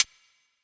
Southside Hihat.wav